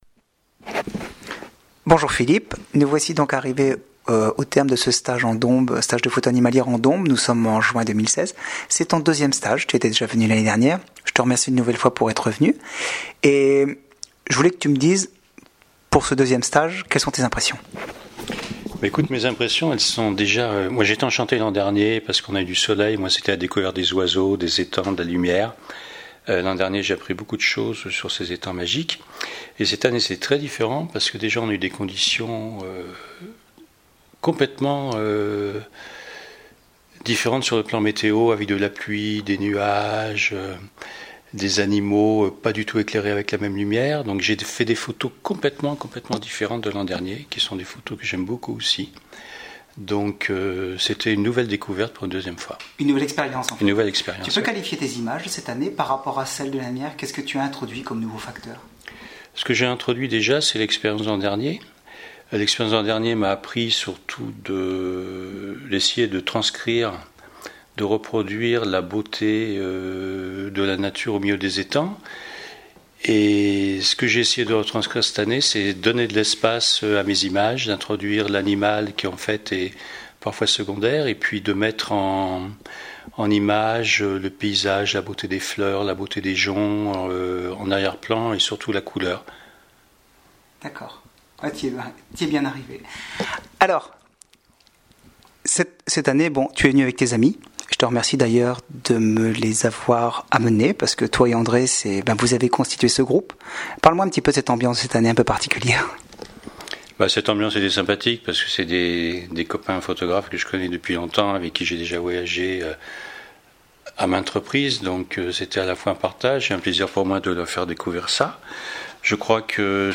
Notre interview pour radio Demoiselle.(cliquez sur le bouton play pour démarrer) :